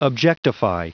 Prononciation du mot objectify en anglais (fichier audio)
Prononciation du mot : objectify